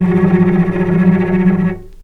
vc_trm-F#3-pp.aif